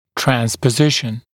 [ˌtrænspə’zɪʃn] [ˌtrɑːn-][ˌтрэнспэ’зишн], [ˌтра:н-]транспозиция (взаимный обмен позициями между зубами)